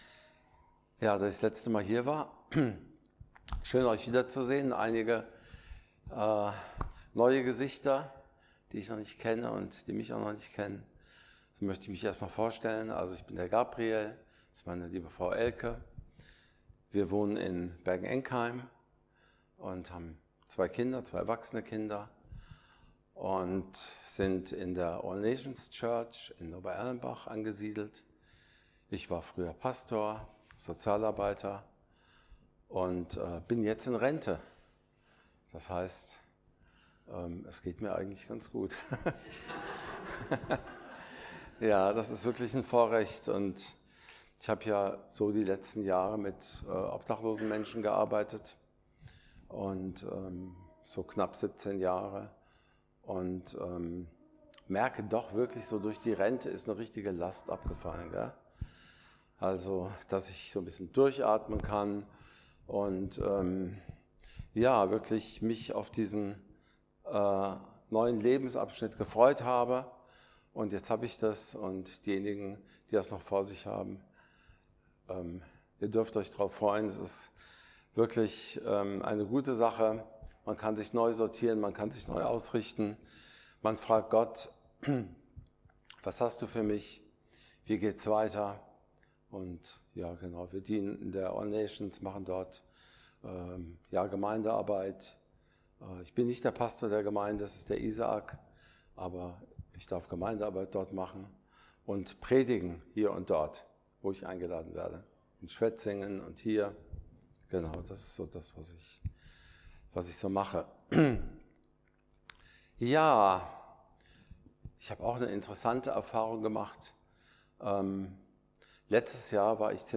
41-57 Dienstart: Predigt Wir sollen den Blickkontakt auf Jesus halten.